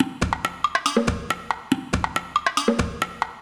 140_perc_2.wav